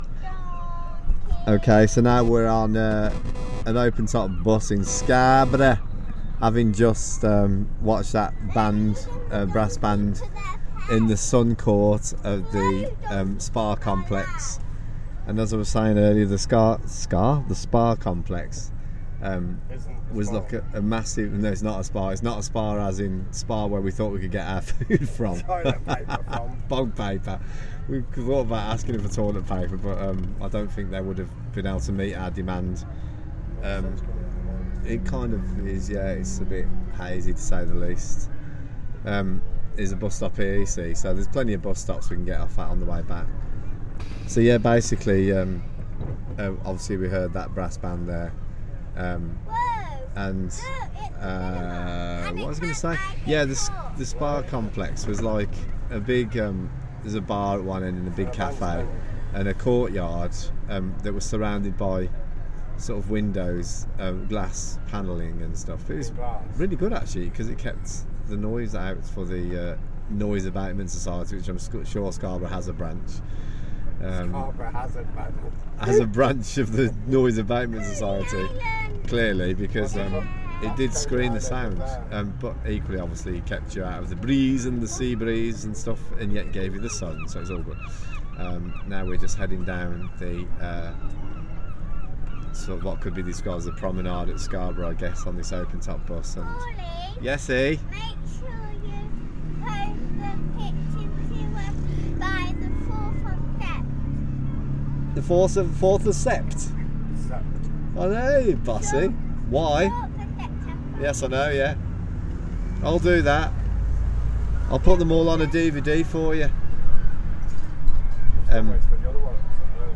Sun, sea, rain, open top bus, pirate ship and plans are all represented here in scarborough sounds.